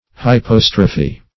Search Result for " hypostrophe" : The Collaborative International Dictionary of English v.0.48: Hypostrophe \Hy*pos"tro*phe\, n. [NL., fr. Gr.